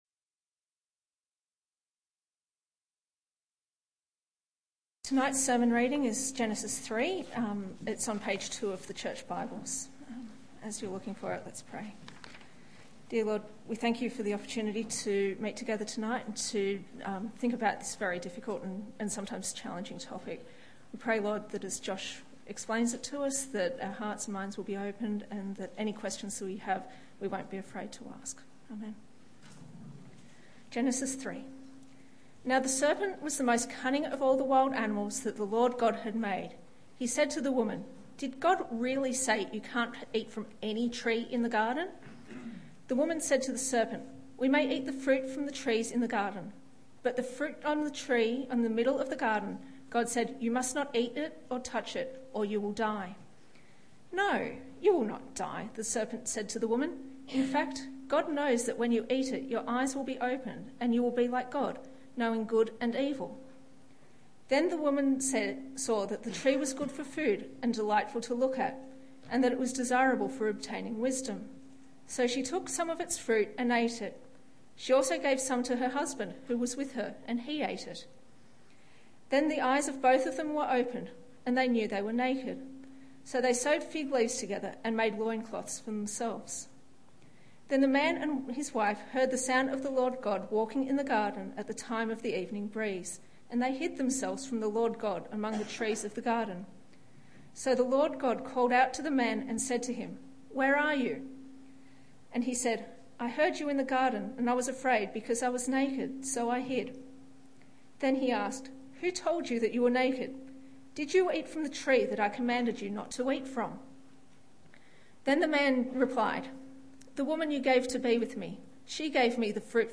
Men, Women And God: Unrestrained Evening Church Edition - Genesis 3